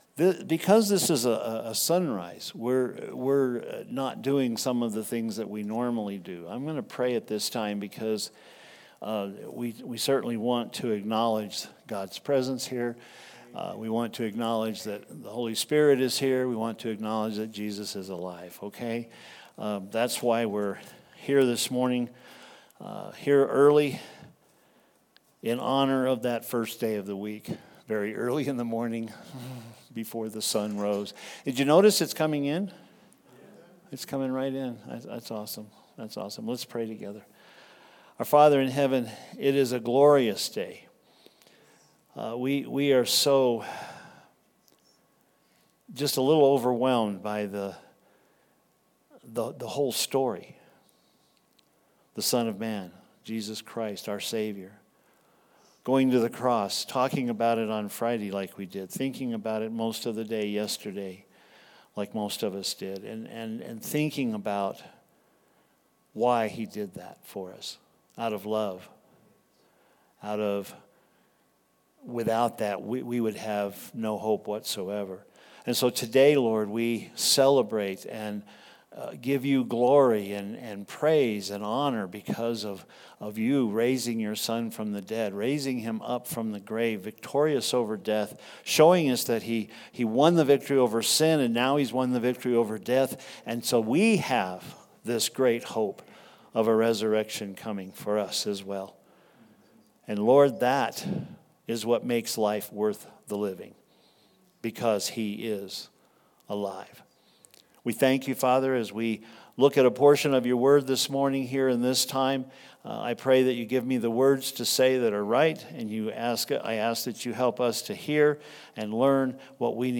Sunrise Easter Service - He Has Done It!
Take a listen to the sunrise service, celebrating the resurrection of Christ.